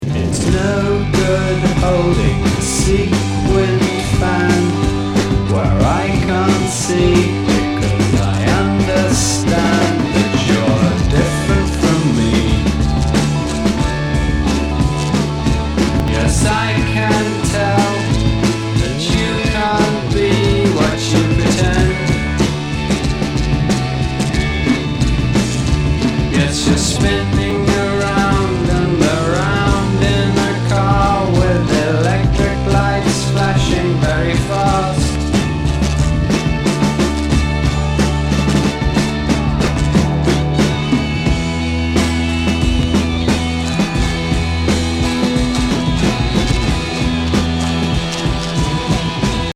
膝崩サイケ～アシッド・フォーク